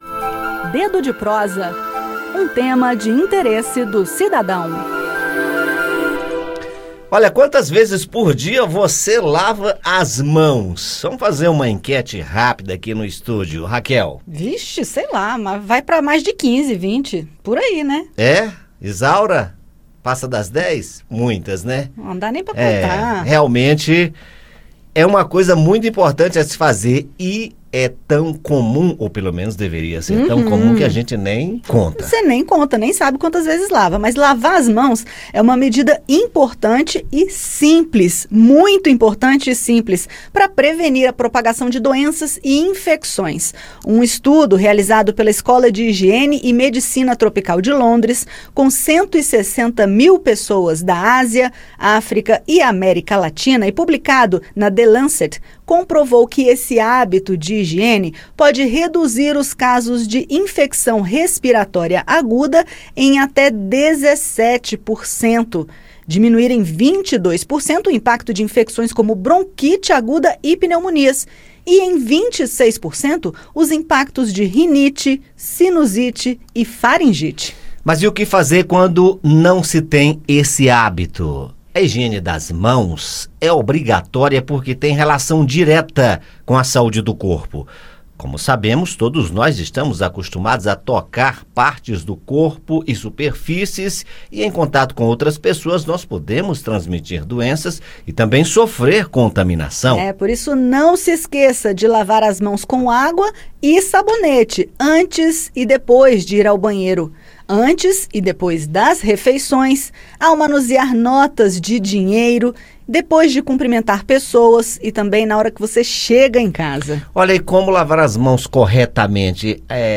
No bate-papo, saiba como fazer a higienização correta das mãos e do corpo e proteja-se de várias doenças e infecções.